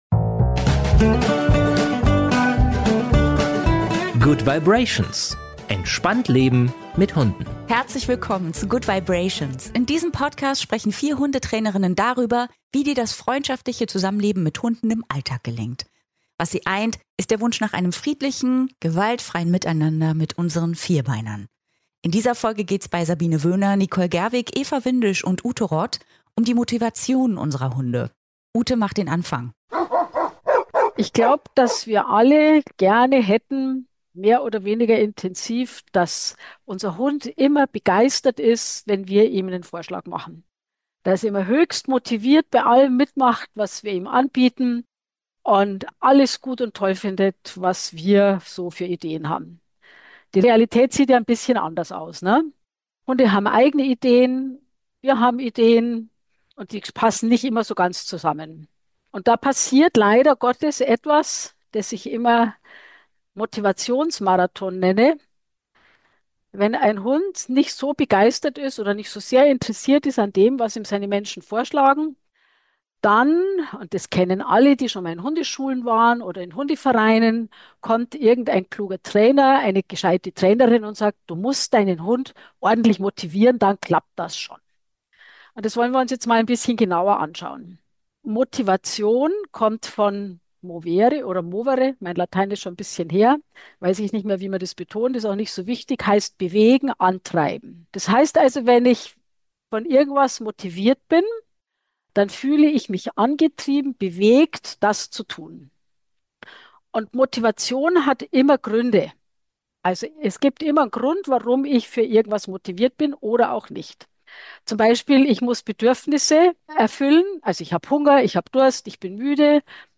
Ein Podcast über das freundschaftliche Zusammenleben mit Hunden im Alltag. Hier diskutieren 4 Hundetrainerinnen über wichtige Themen im Leben mit Vierbeinern.